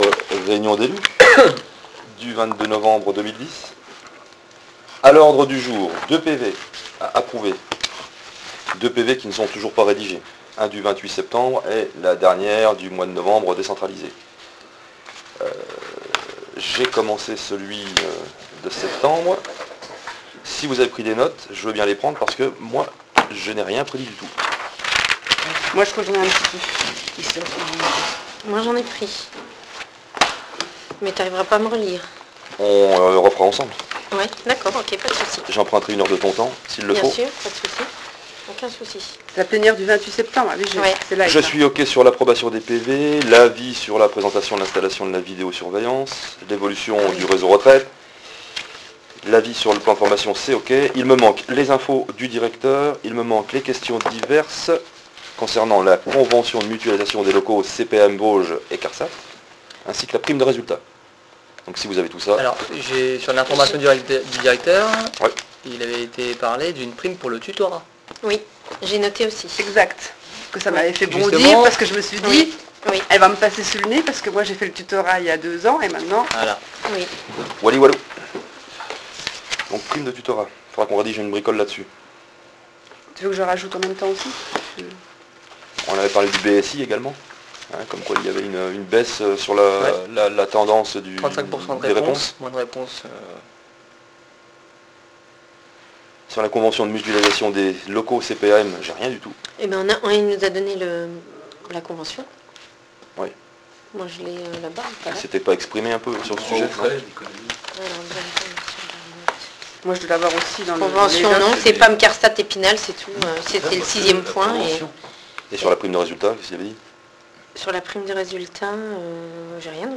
reunionelus_mun_10.wav